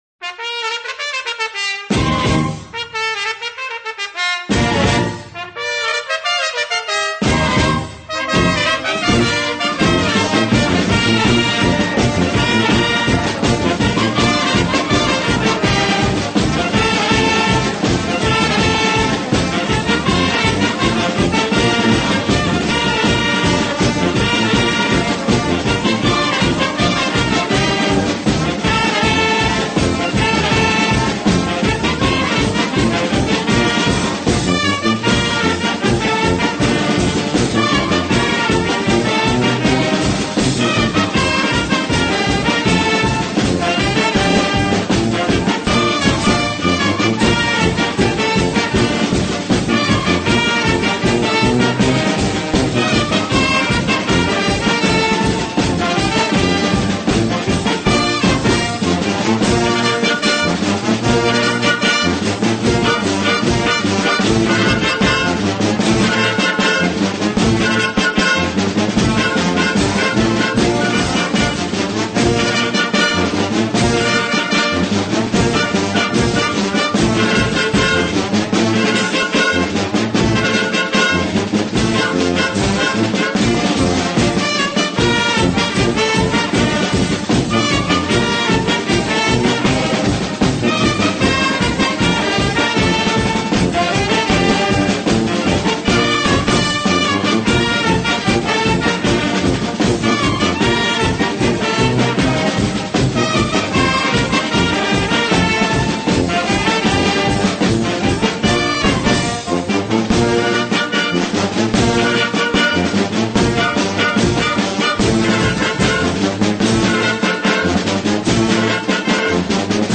nnnnnnnnn Le chant basque: La Pitchoulie